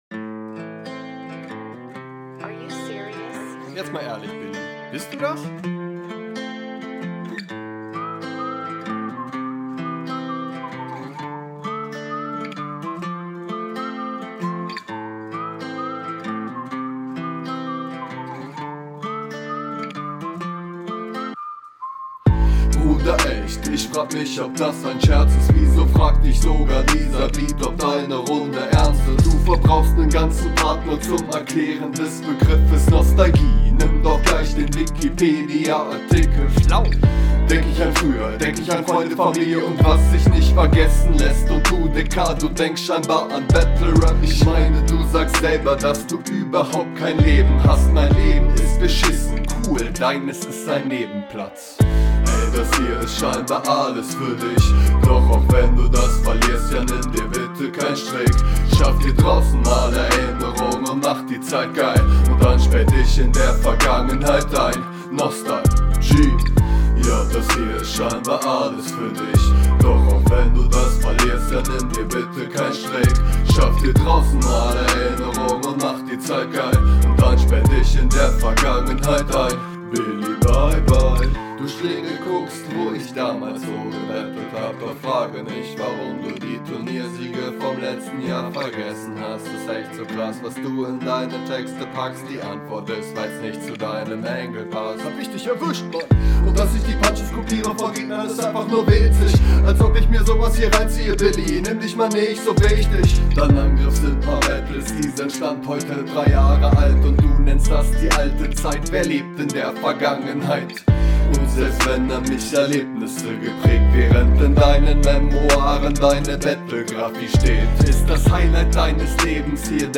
Dein eher schmutziger Mix funktioniert hier mit dem Beat etwas besser als in den anderen …